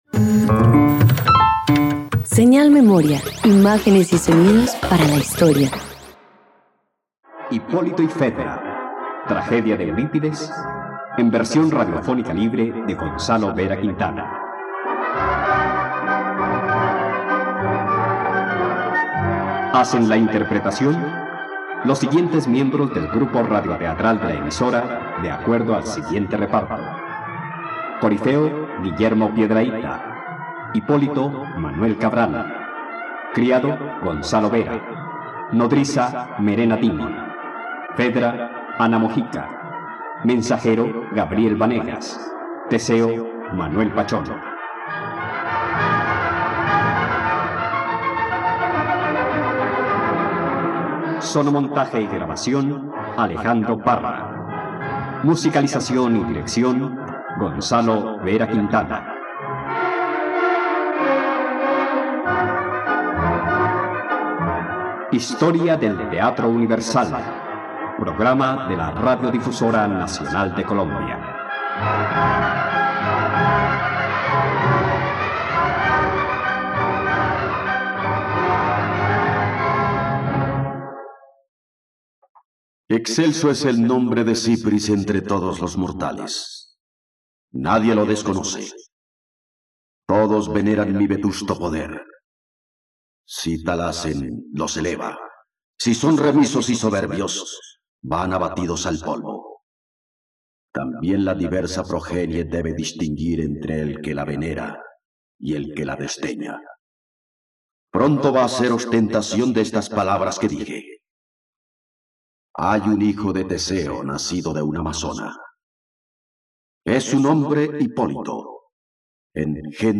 ..Radioteatro. Escucha la adaptación de la obra "Hipólito y Fedra" del poeta trágico griego Eurípides en la plataforma de streaming RTVCPlay.